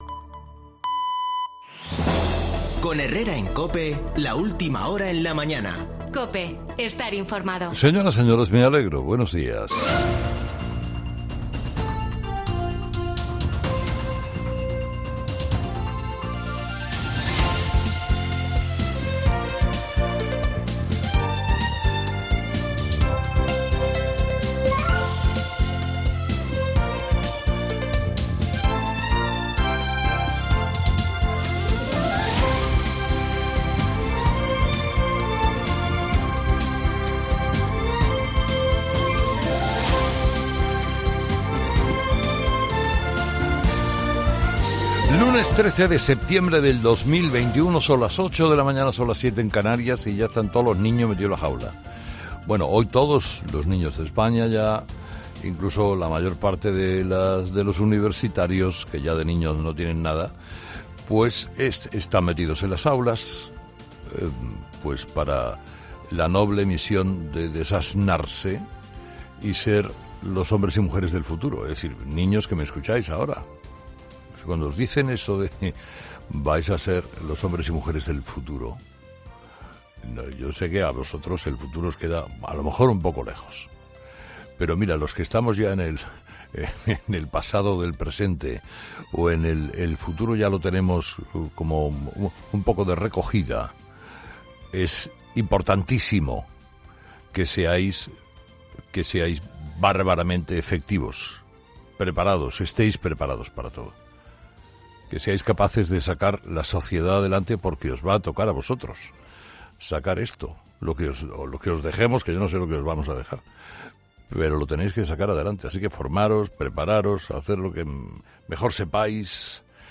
[ESCUCHA AQUÍ EL MONÓLOGO DE HERRERA DE LUNES 13 DE SEPTIEMBRE]